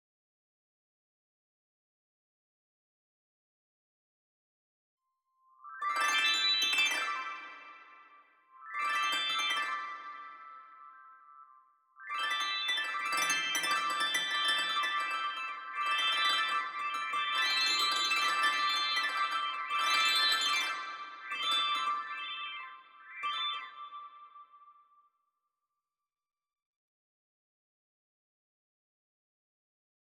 As the scan moves from the center outward, X-ray sources detected by Chandra are translated into harp sounds.